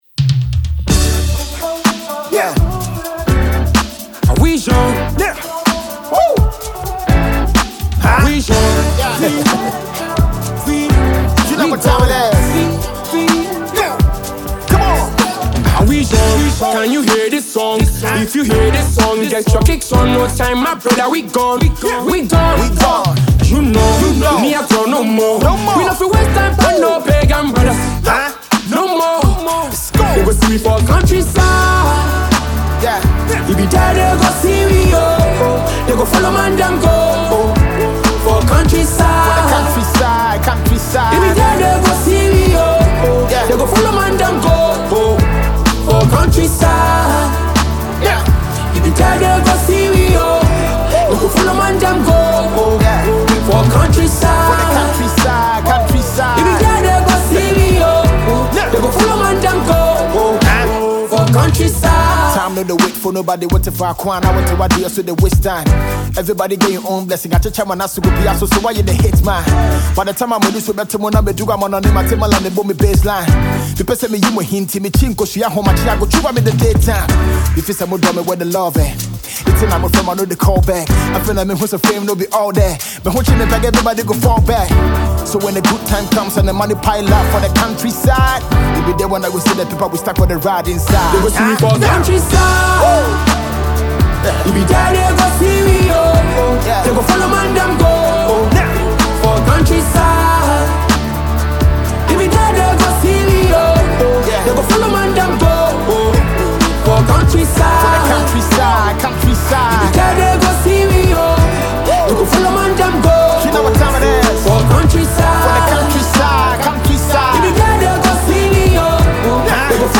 the live version